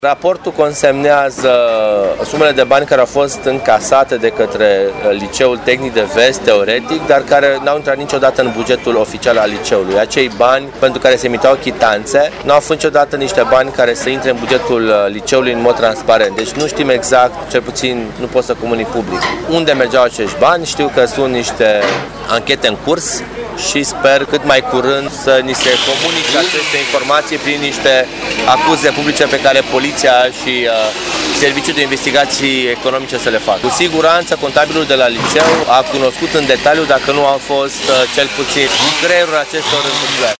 Viceprimarul Ruben Lațcău a declarat că, din raportul Corpului de Control al Primarului, reiese că banii încasați din închirierea spațiilor, pentru care erau emise chitanțe, nu intrau în bugetul liceului.